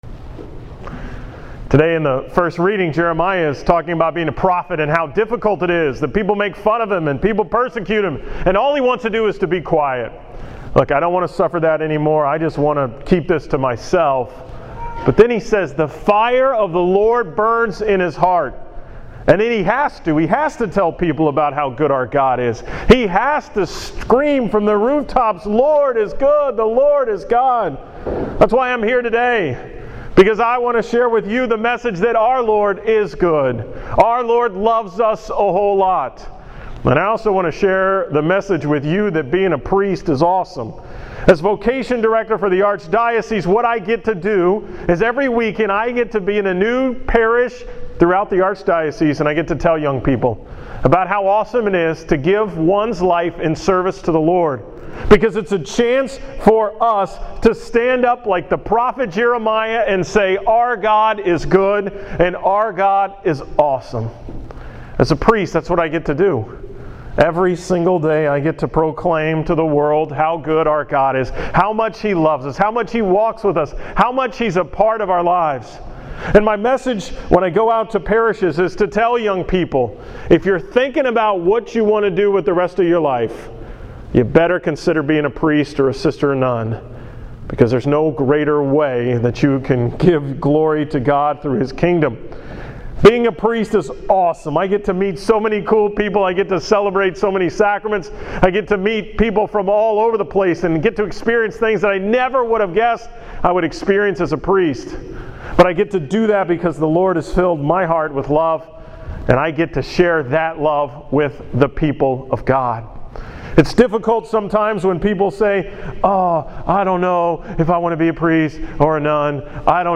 From the 22nd Sunday in Ordinary time at St. John's in Alvin